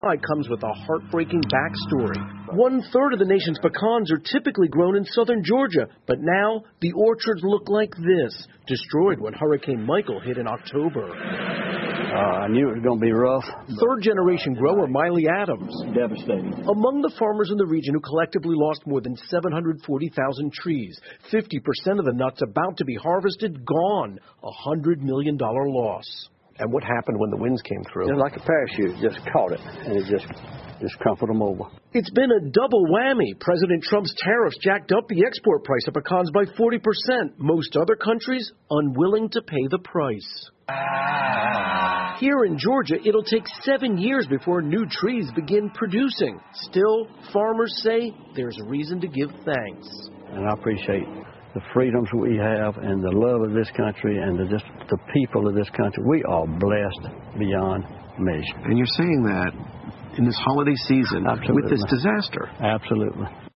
NBC晚间新闻 美国农民遭受飓风和关税双重打击 听力文件下载—在线英语听力室